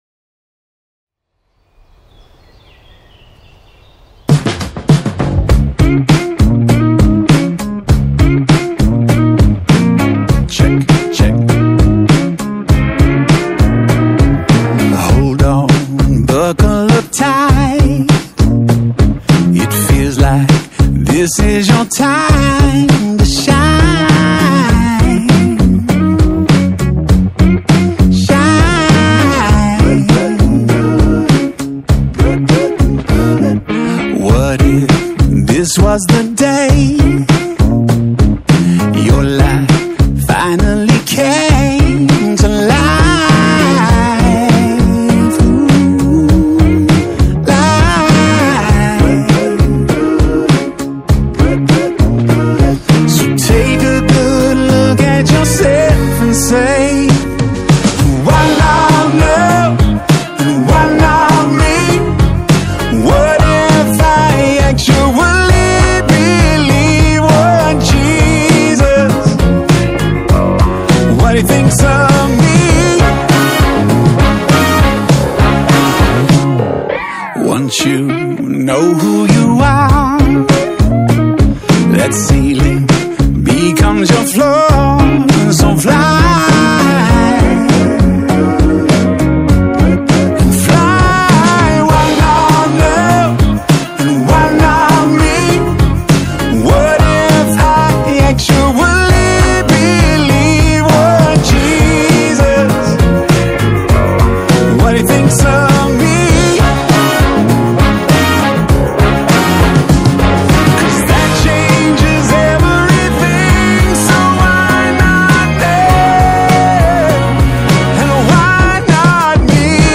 contemporary Christian band
percussion
bass